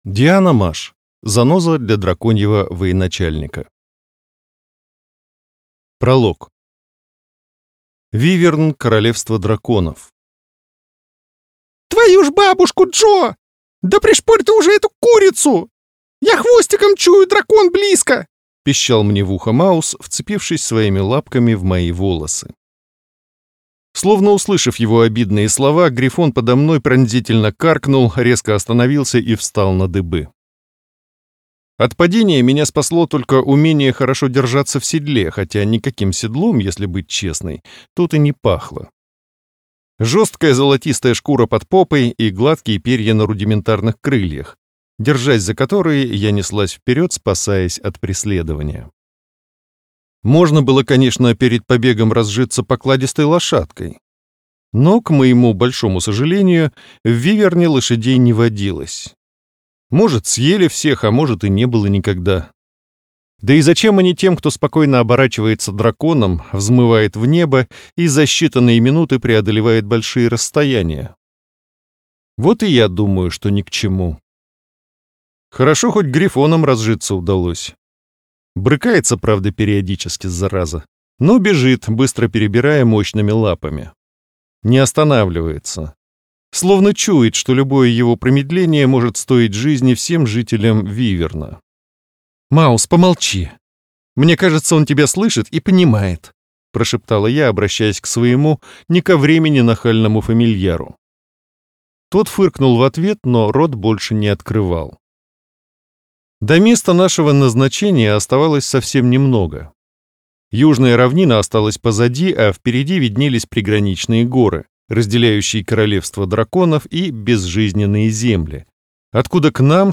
Аудиокнига Заноза для драконьего военачальника | Библиотека аудиокниг